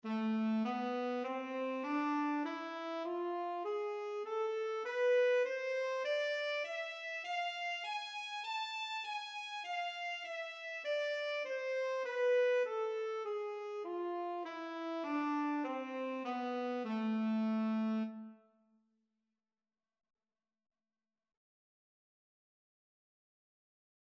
SaxScale.mp3